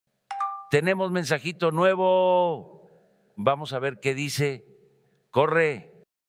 Tono para mensaje nuevo también sound effects free download